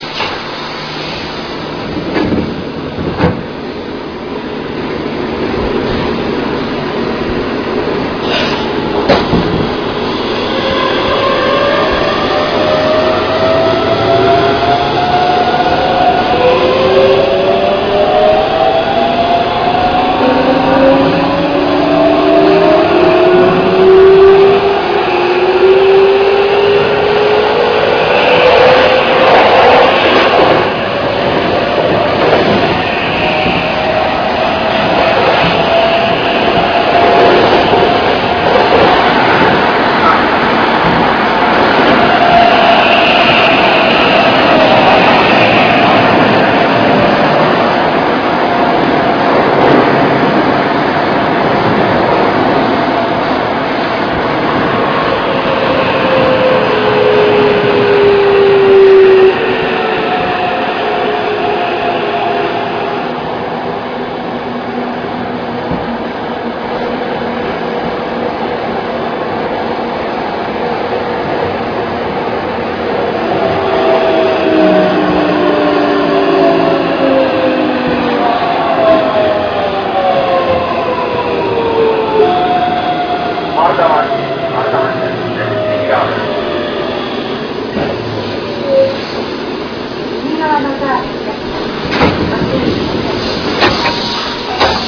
走り装置は前述の通り、7000系とほとんど同じです。異なるのは台車の形式くらいですが、 走行音には影響はなさそうです。